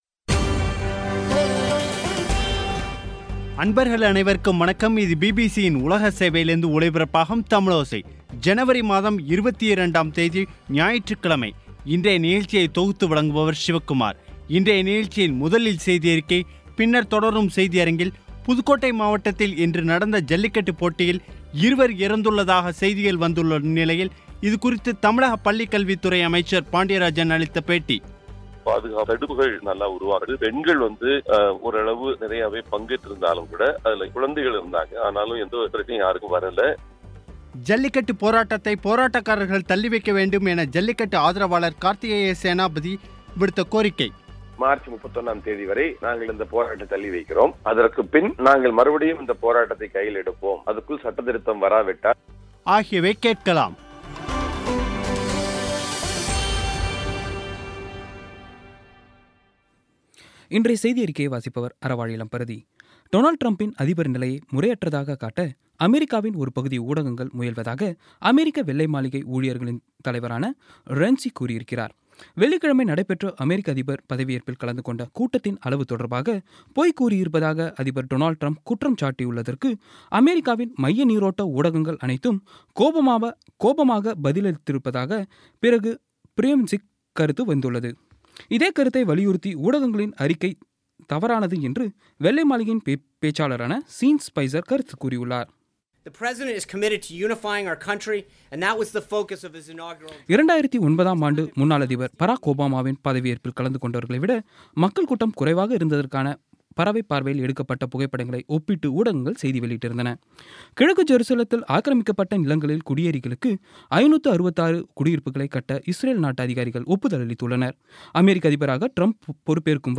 இன்றைய நிகழ்ச்சியில் முதலில் செய்தியறிக்கை, பின்னர் தொடரும் செய்தியரங்கில்
புதுக்கோட்டை மாவட்டத்தில் இன்று நடந்த ஜல்லிக்கட்டு போட்டியில் காளை மாடு முட்டியதில் இருவர் இறந்துள்ளதாக செய்திகள் வந்துள்ள நிலையில், இது குறித்து பள்ளி கல்வித் துறை அமைச்சர் பாண்டியராஜன் அளித்த பேட்டி